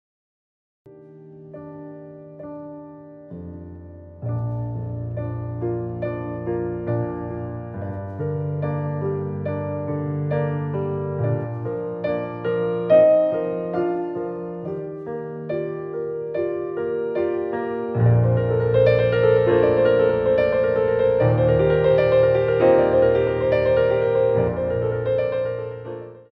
Ballet Class Music For First Years of Ballet